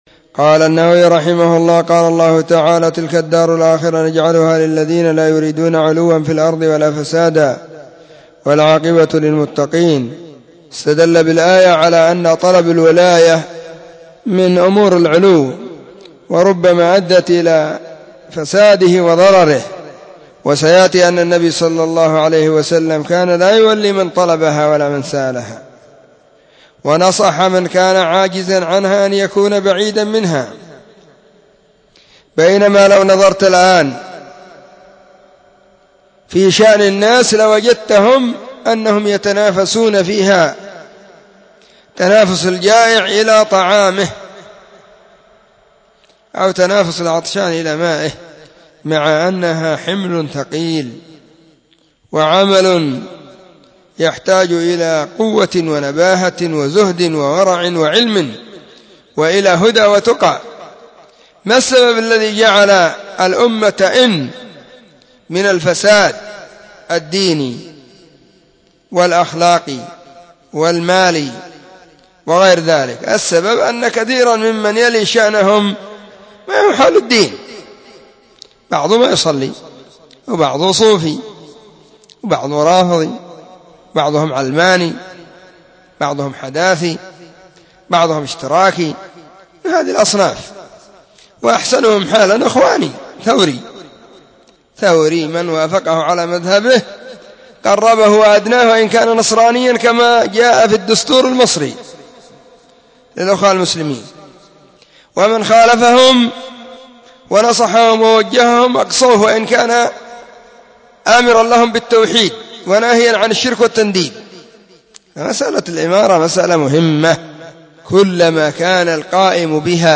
💢نصيحة قيمة بعنوان :نصيحة عن الامارة من درس رياض الصالحين*
نصيحة_عن_الامارة_من_درس_رياض_الصالحين.mp3